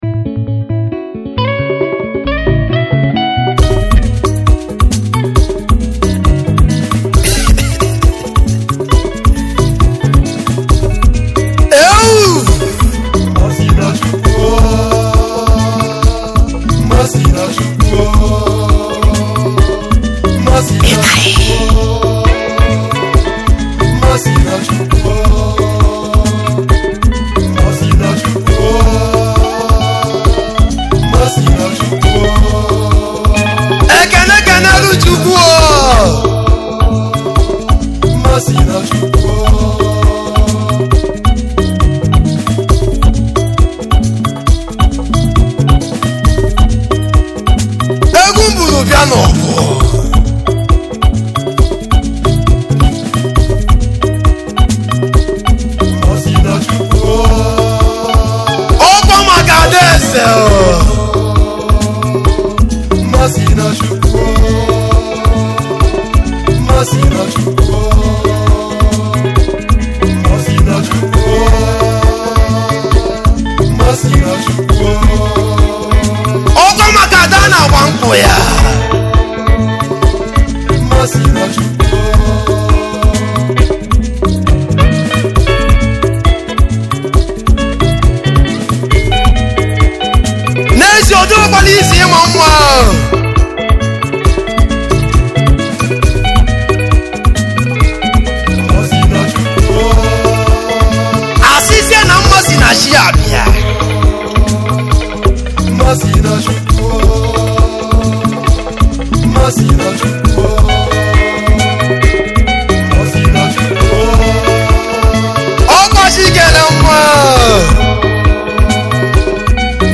highlife music band